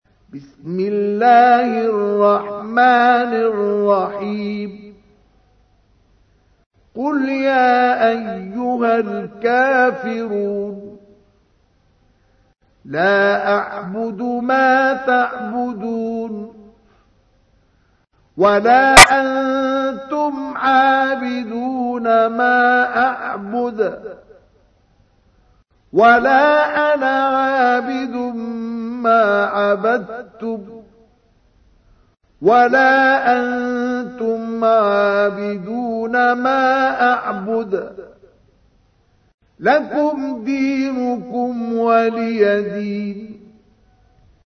تحميل : 109. سورة الكافرون / القارئ مصطفى اسماعيل / القرآن الكريم / موقع يا حسين